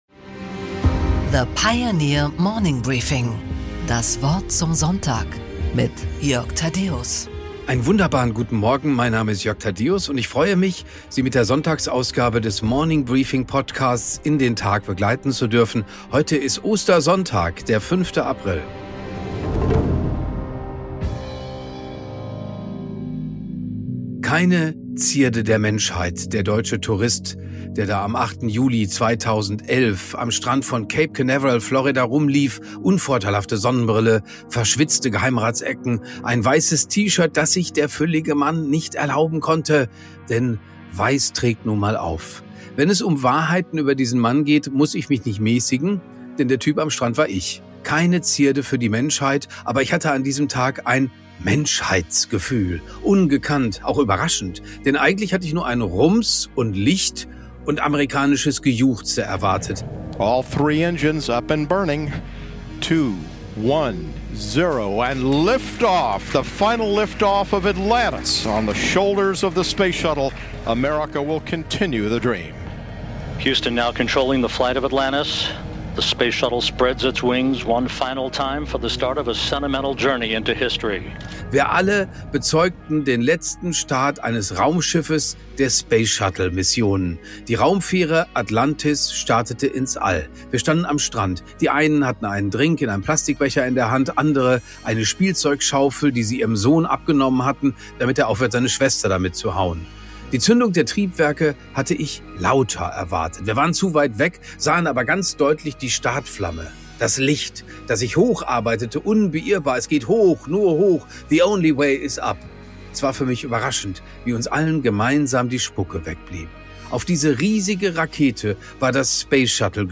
Jörg Thadeusz präsentiert die Morning Briefing Weekend Edition.